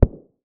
bass_small.ogg